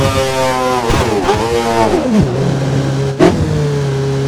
Index of /server/sound/vehicles/lwcars/f1
slowdown_slow.wav